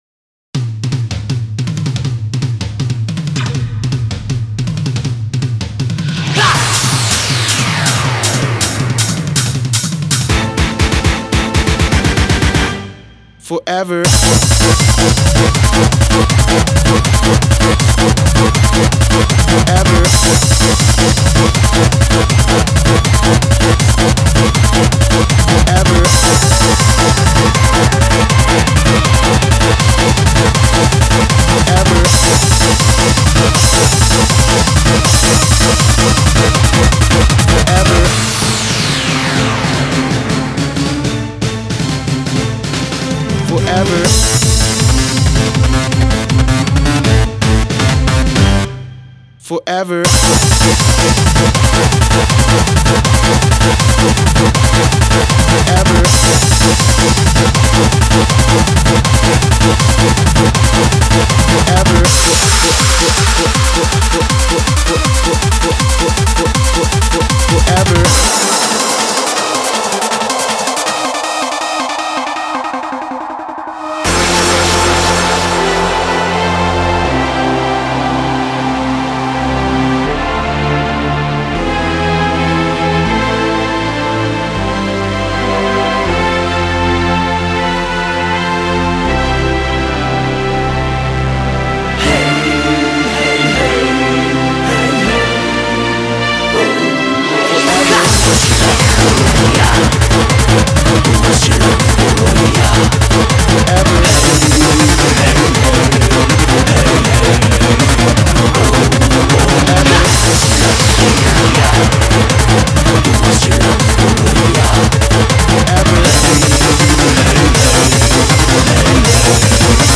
BPM80-160
Audio QualityPerfect (High Quality)
Genre: 00's TECHNO.